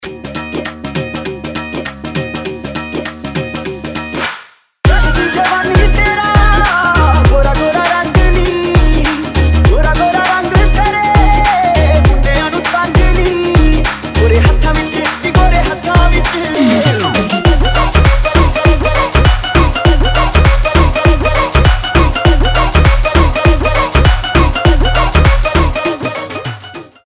• Качество: 320, Stereo
веселые
заводные
восточные
индийские
индийские мотивы
веселая музыка